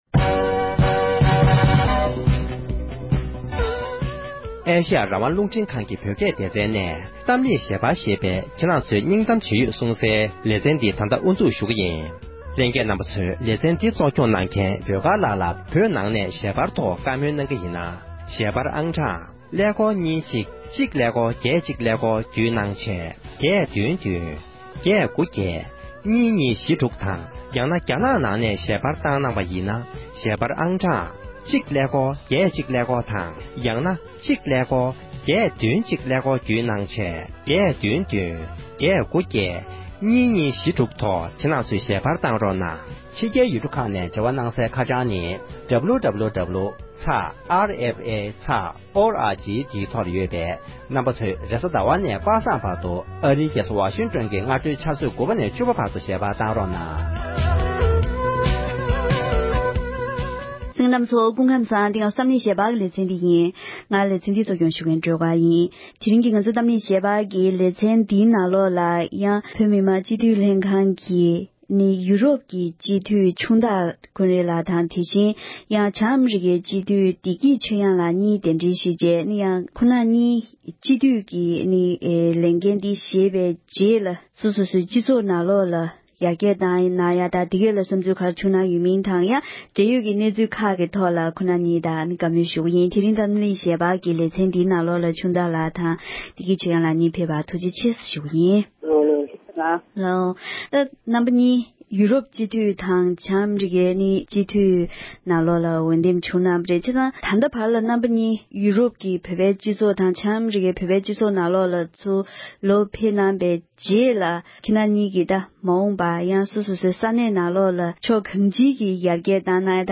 བྱང་ཨ་རི་དང་ཡུ་རོབ་ཀྱི་སྤྱི་འཐུས་གཉིས་དང་ལྷན་དུ་རང་རང་སོ་སོའི་བཙན་བྱོལ་བོད་མིའི་སྤྱི་ཚོཊ་ཀྱི་གནས་སྟངས་ཐད་གླེང་མོལ།